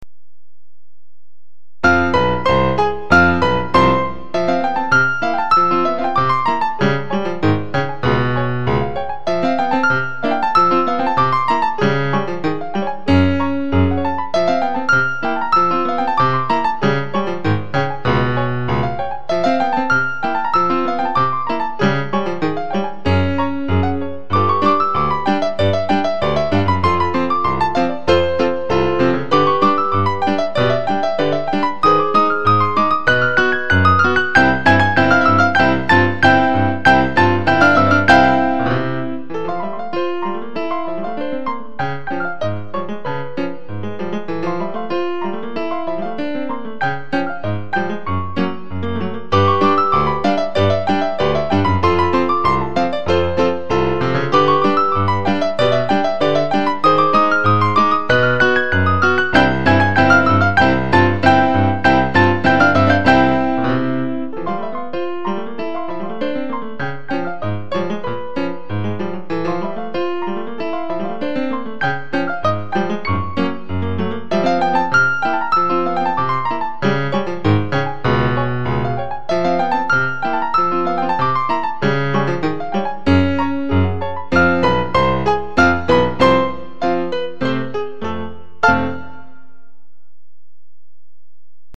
La mélodie passe sans cesse d'un pianiste à l'autre.